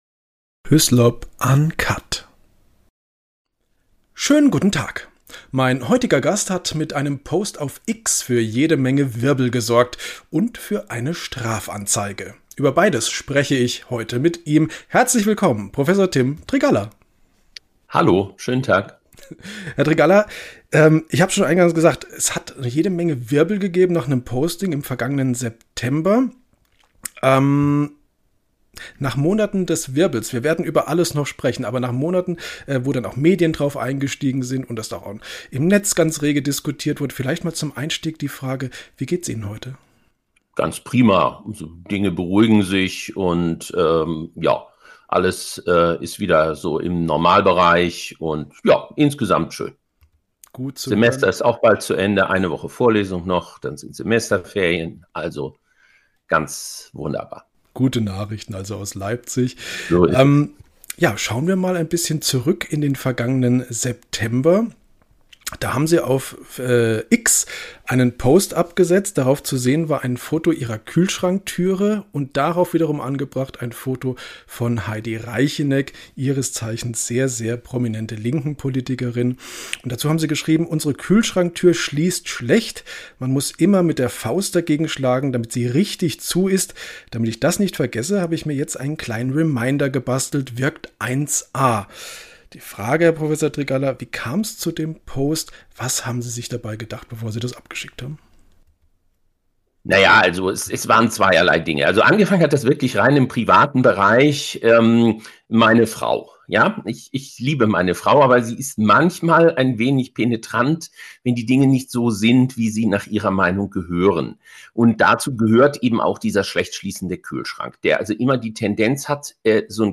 Ich sprach mit ihm über den Beitrag, Auswirkungen an seiner Universität und den Ausgang der Strafanzeige.